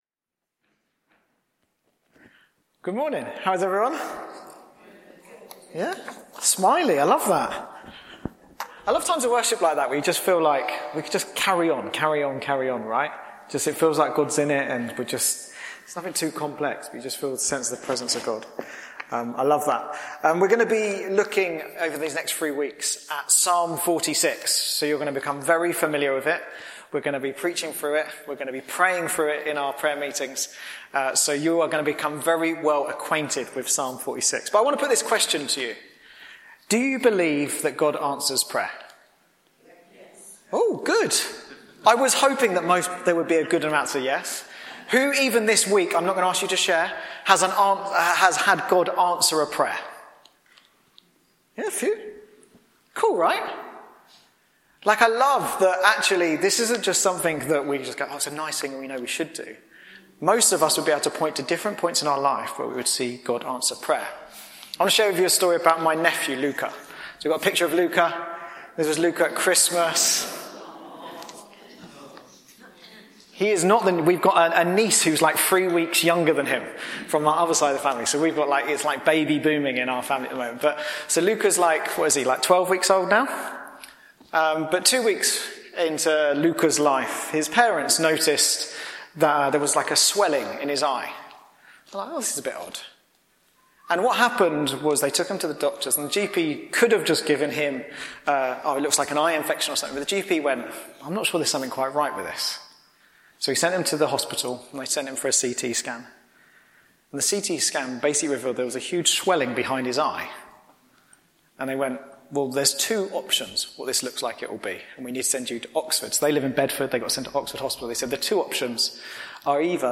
Bassett Street Sermons